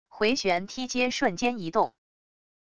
回旋踢接瞬间移动wav音频